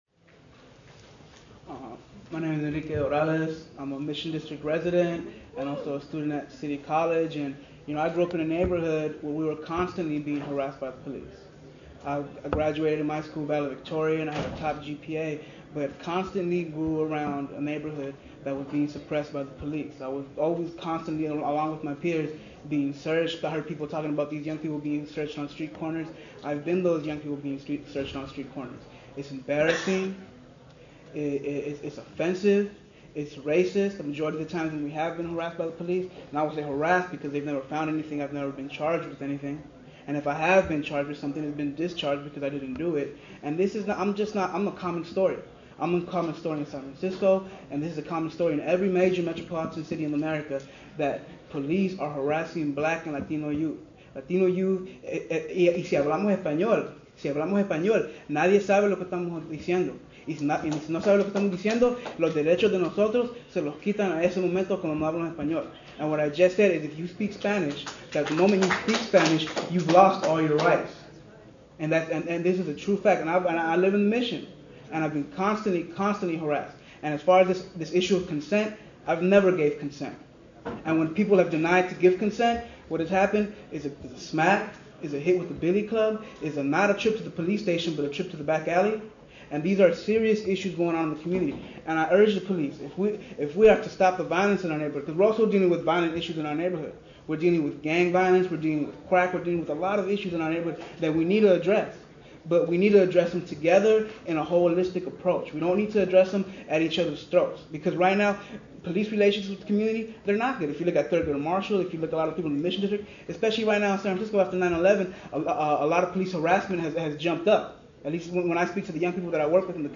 At the Jan. 8th SF Police Commission hearing, Thurgood Marshall students and other community members spoke out against racist harrassment, searches and assaults by police, which disproportionately target blacks and latinos.